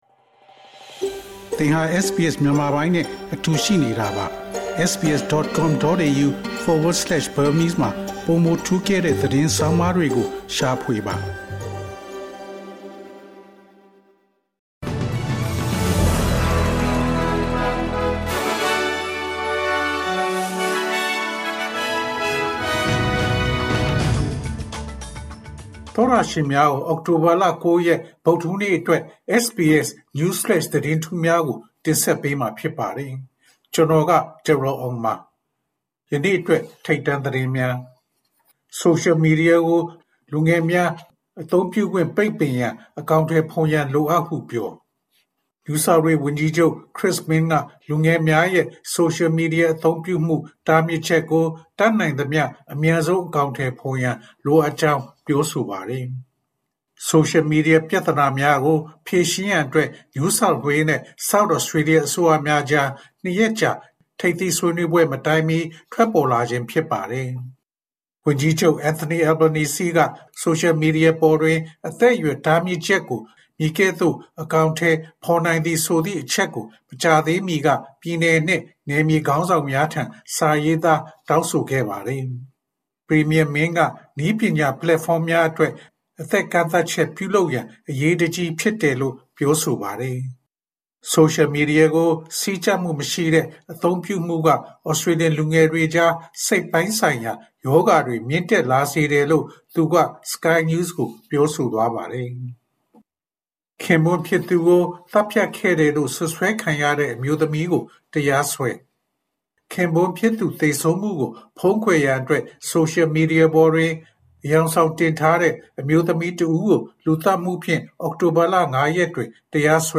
အောက်တိုဘာလ ၉ ရက် တနင်္လာနေ့ SBS Burmese News Flash သတင်းများ။